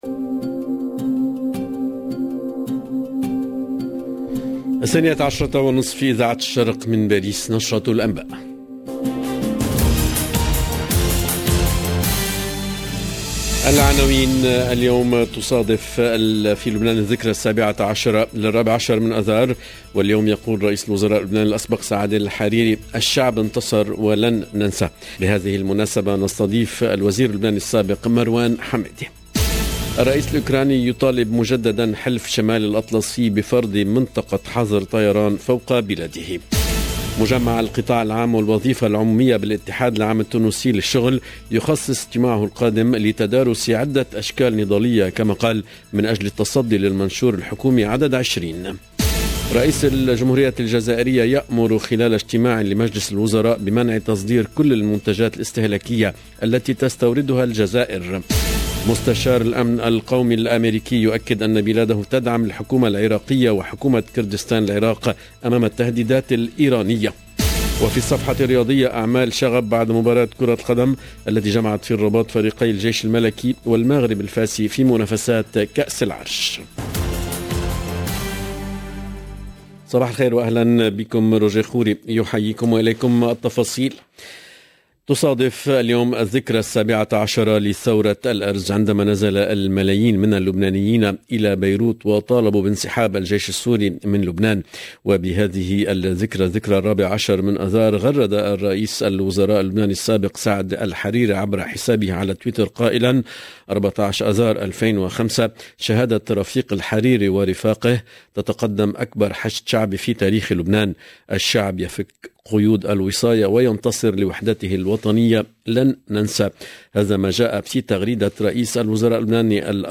LE JOURNAL DE MIDI 30 EN LANGUE ARABE DU 14/03/22